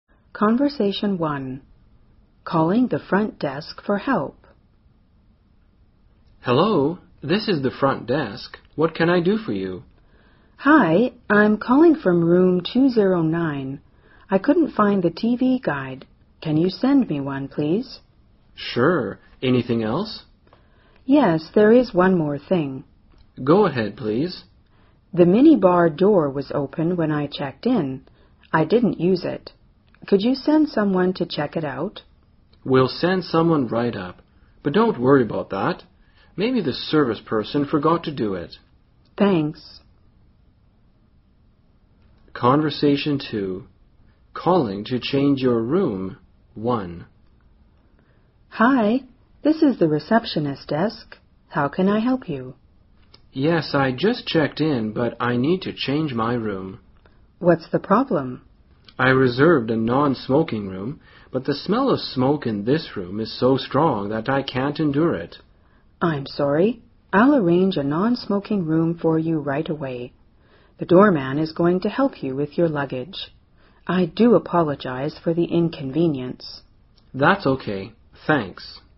【对话1：打电话到前台求助】
【对话2：打电话要求换房间（1）】